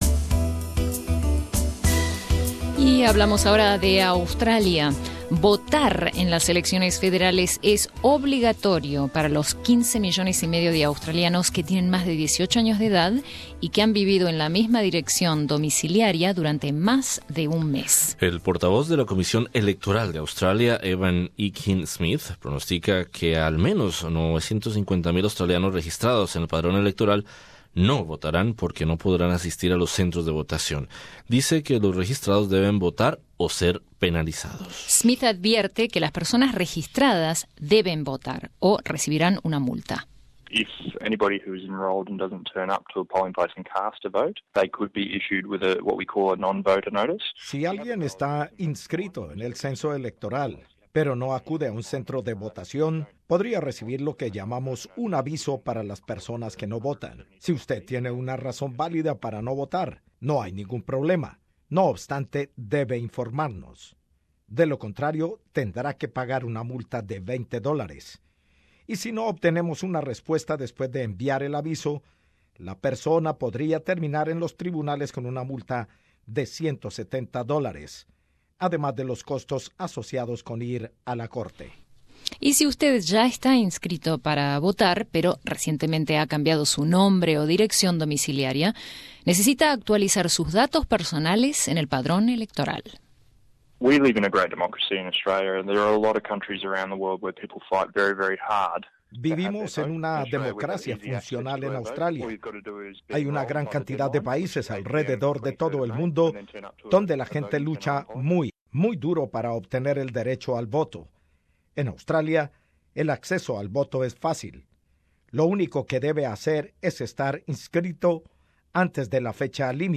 Votar en las elecciones federales australianas es obligatorio. Escucha nuestro informe sobre cómo funciona el sistema electoral, antes de acudir a a las urnas el 2 de julio.